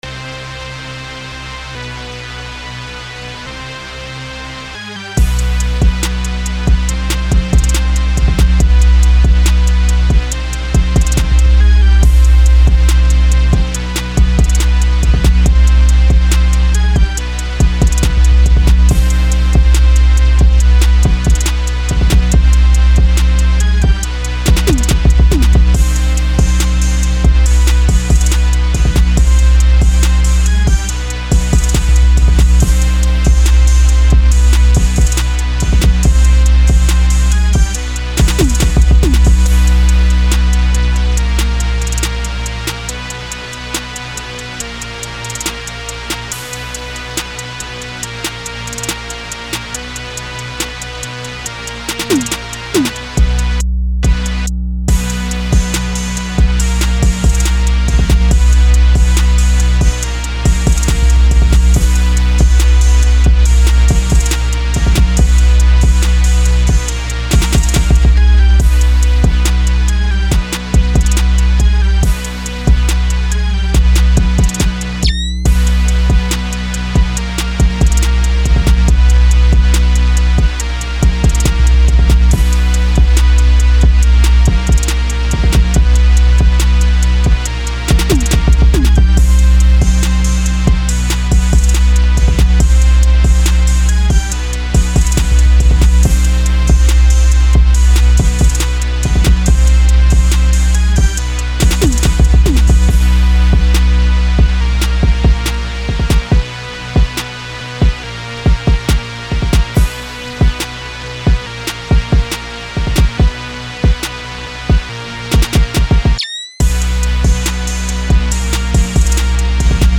Жанр: trap,hip hop